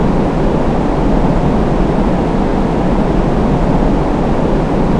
320wind.wav